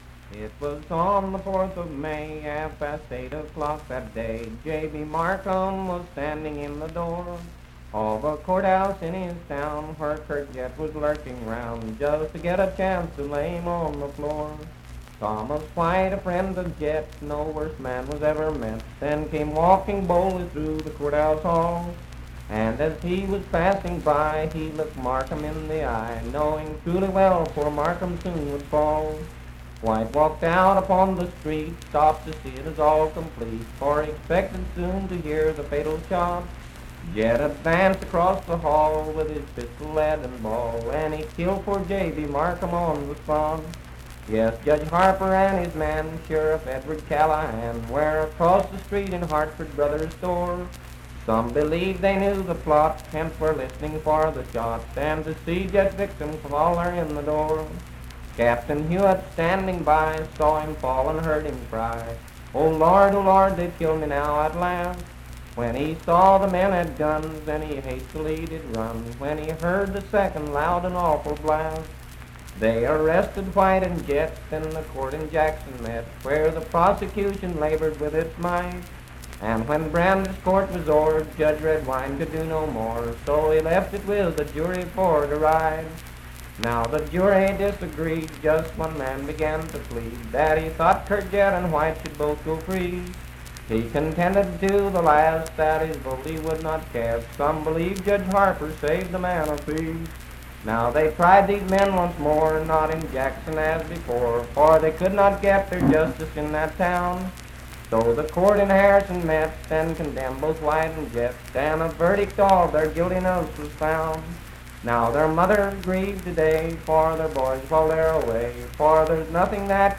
Unaccompanied vocal performance
Voice (sung)
Spencer (W. Va.), Roane County (W. Va.)